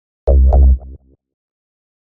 Scifi 11.mp3